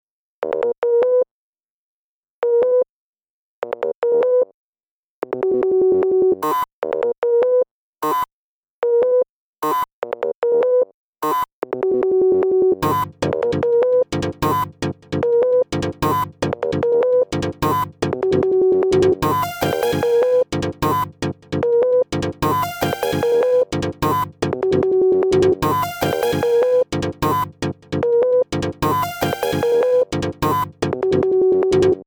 Ago d'acciaio, piastra, piatti, acido della batteria
knobs a 360 gradi